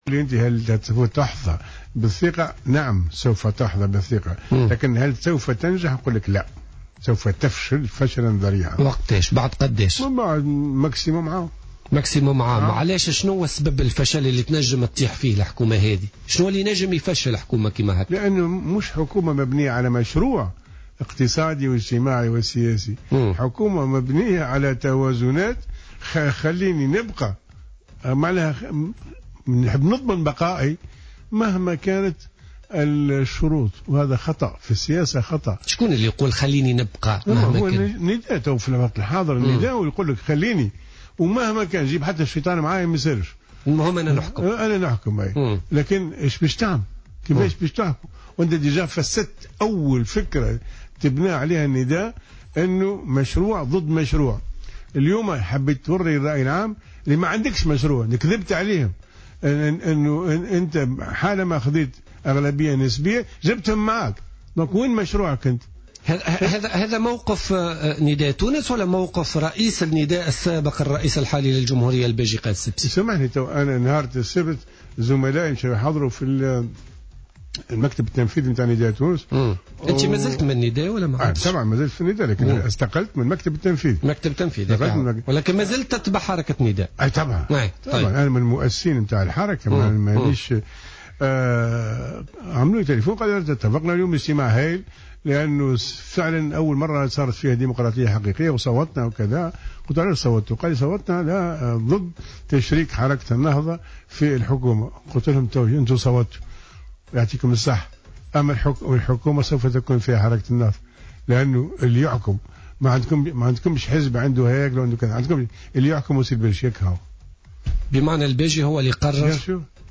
قال الإعلامي الطاهر بن حسين ضيف بوليتيكا اليوم إن رئيس الجمهورية، والرئيس السابق لحركة نداء تونس الباجي قائد السبسي هو صاحب القرار الأول والأخير في نداء تونس وهو من دفع نحو إشراك النهضة في حكومة الحبيب الصيد على اعتبار أنه يرغب في تحقيق الاستقرار مهما كان الثمن حتى وإن تشارك في الحكم مع النهضة.